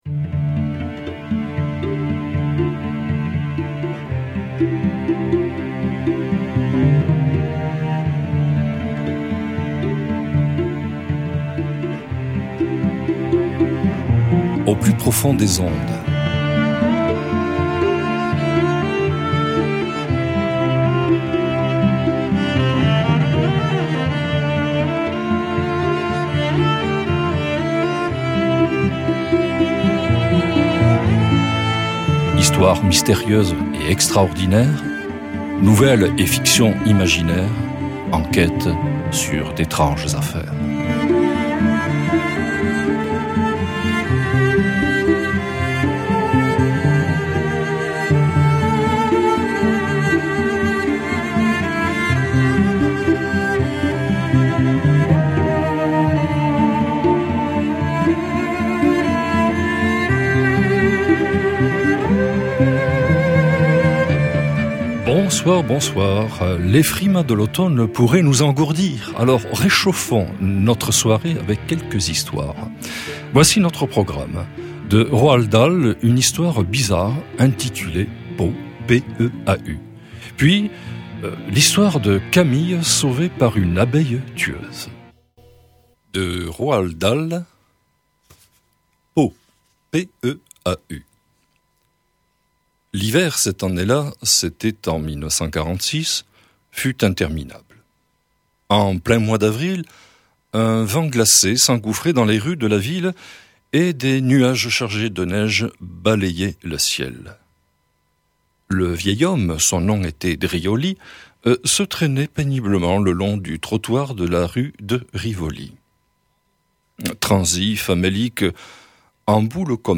On commence en lecture avec une histoire bizarre intitulée Peau, de Roald Dahl.